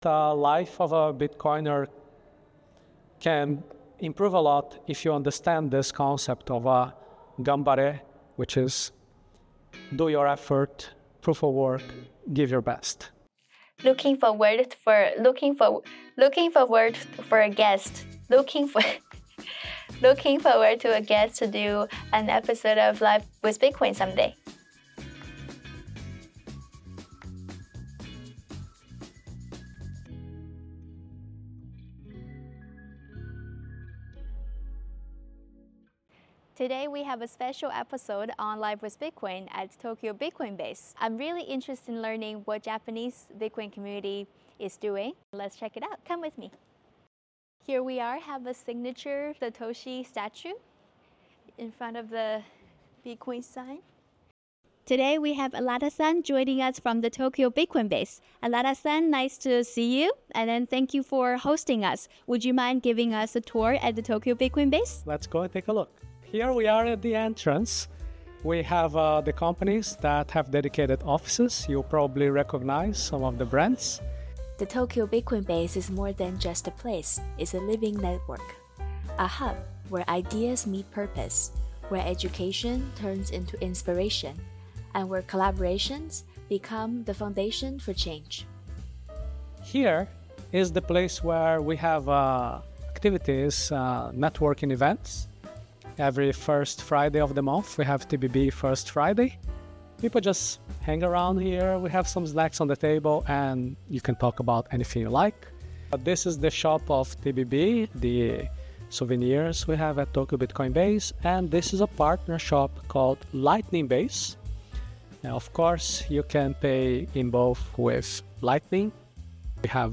Join me as I explore the space, meet the people behind it, and see how Bitcoin comes to life here in Japan.